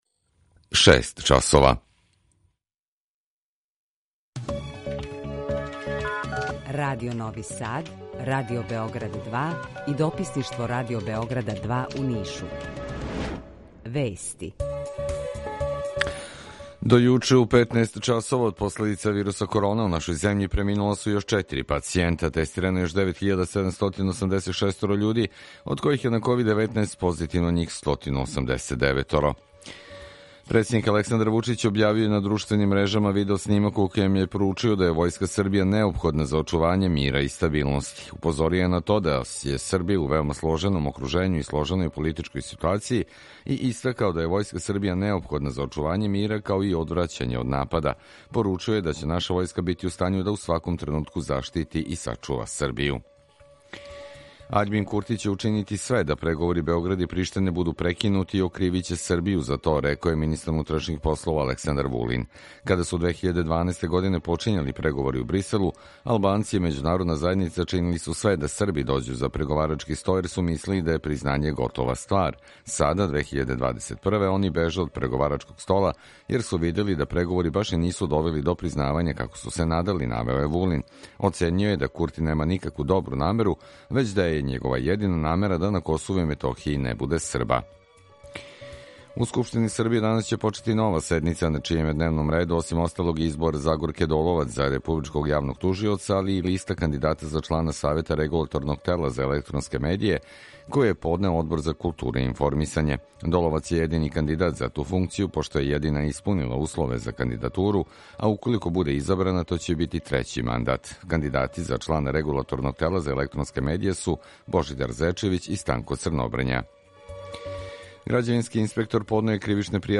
Укључење Косовске Митровице
Jутарњи програм заједнички реализују Радио Београд 2, Радио Нови Сад и дописништво Радио Београда из Ниша. Cлушаоци могу да чују најновије информације из сва три града, најаве културних догађаја, теме које су занимљиве нашим суграђанима без обзира у ком граду живе.
У два сата, ту је и добра музика, другачија у односу на остале радио-станице.